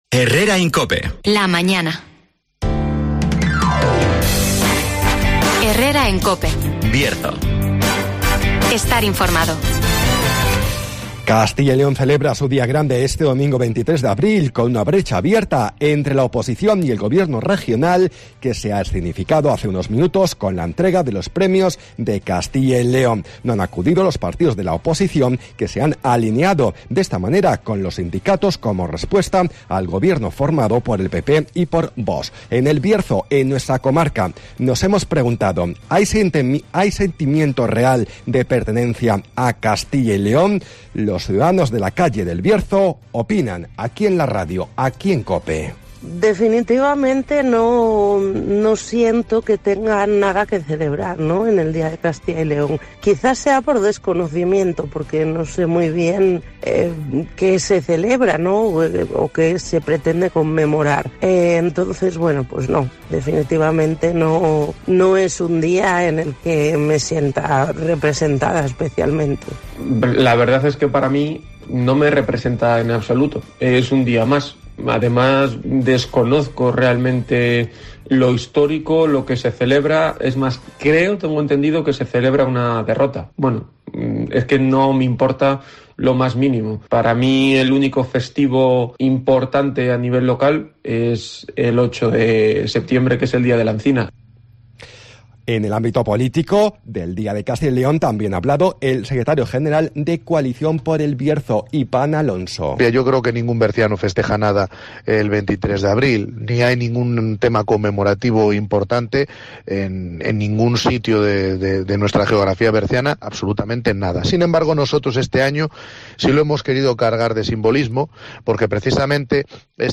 -Resumen de las noticias -El tiempo -Agenda - ‘El Pazo de Lourizán’ y la novela de saga familiar serán protagonistas este sábado en la Feria del Libro de Ponferrada (Entrevista